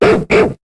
Cri de Couafarel dans Pokémon HOME.